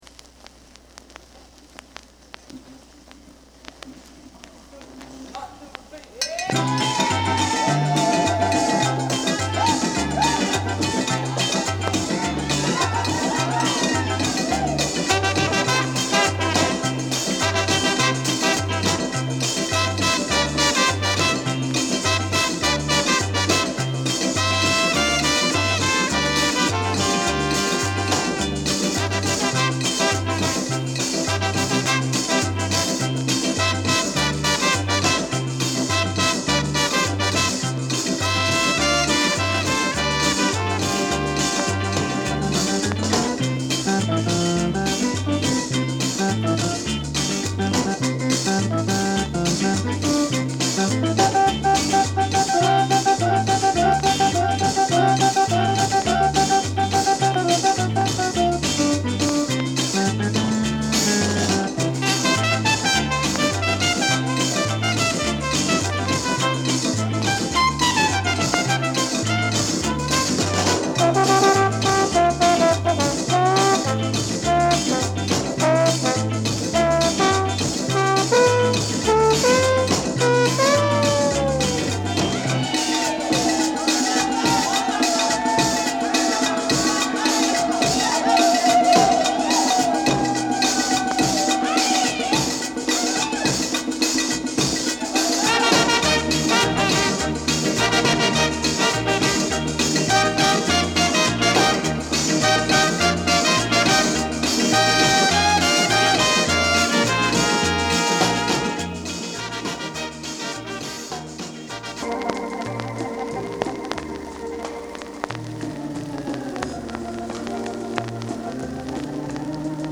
Calypso / Mento / Instrumental カリブの陽光を感じさせる開放的なアイランド・サウンド。
多幸感溢れるインストゥルメンタル！
カリプソの軽快なリズムが心地よい一曲。ホーン・セクションの、どこか長閑な空気感が、聴く者を一瞬にして南の島へと誘います！